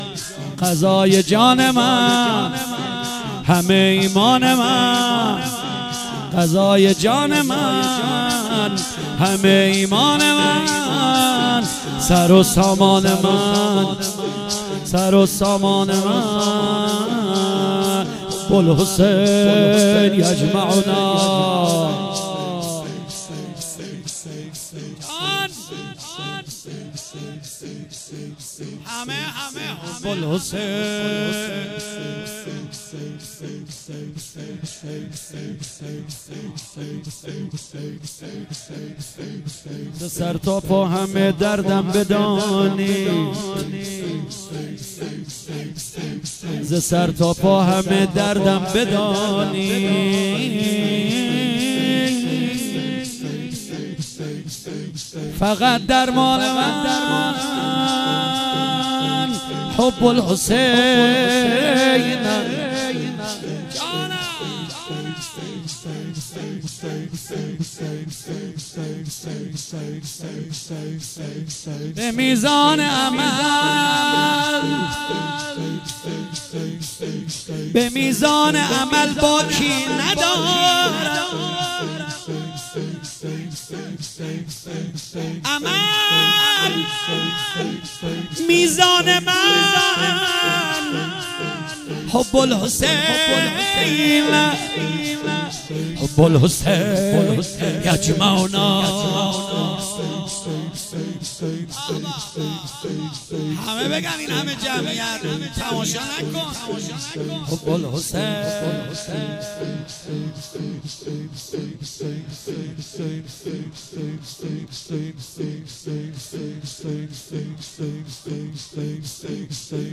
شب سوم محرم 97 - شور - حب الحسین